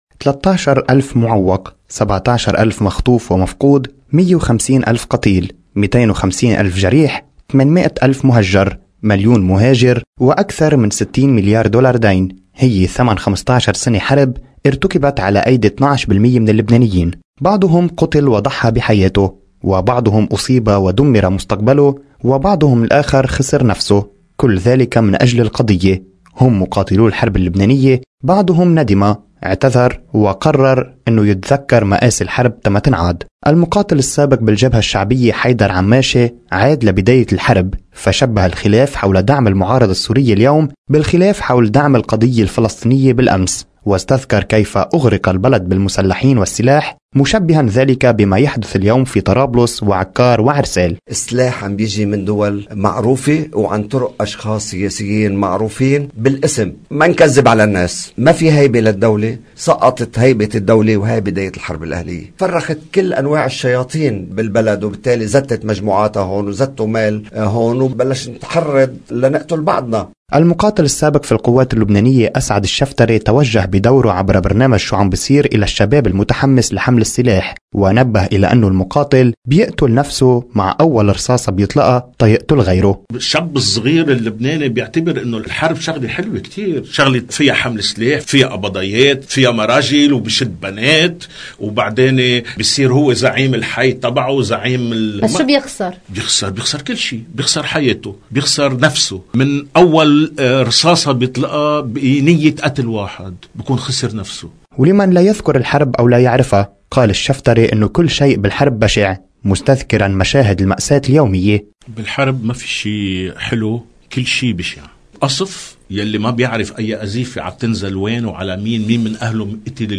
في ذكرى 13 نيسان، شهادات لمقاتلين ندموا